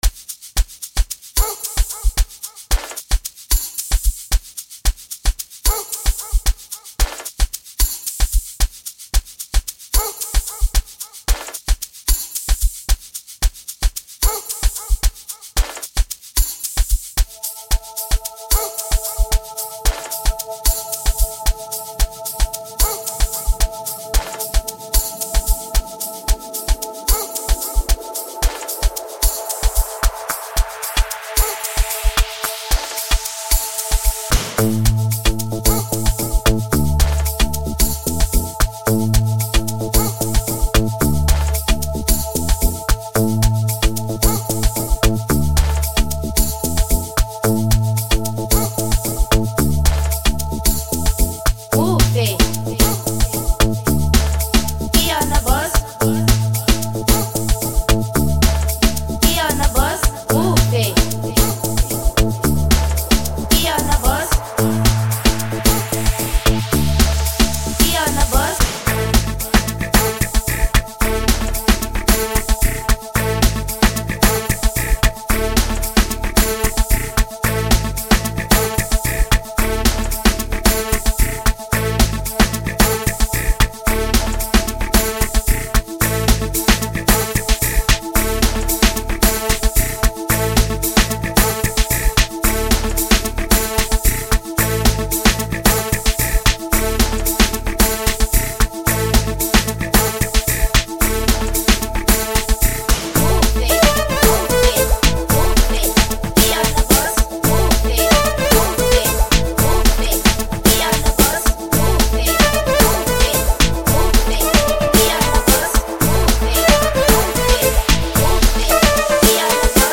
will get everyone pumped up this new month
with a sharper sound hitting the eardrum